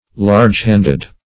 Search Result for " large-handed" : The Collaborative International Dictionary of English v.0.48: Large-handed \Large"-hand`ed\, a. Having large hands.